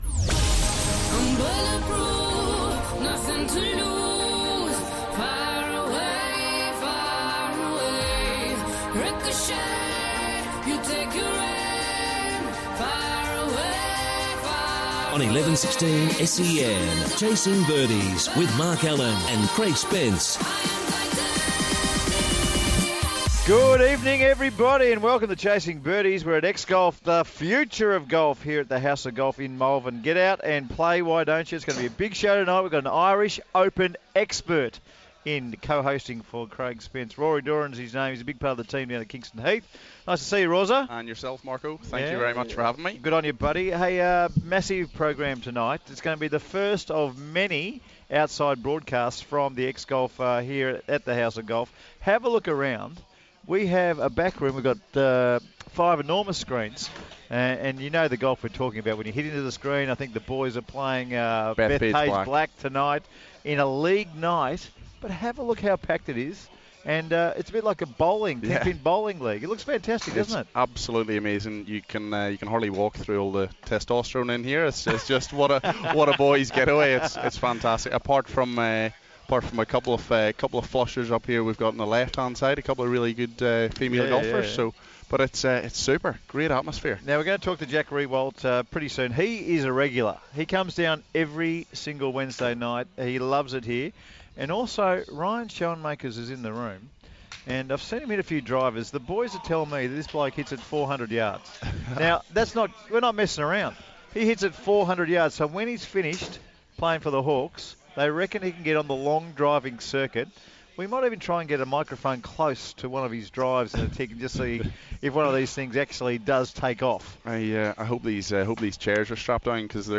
host this week's show from the House of Golf in Malvern. They preview this week's Irish Open at Royal County Down and are joined by Richmond's Jack Riewoldt and Hawthorn's Ryan Schoenmakers.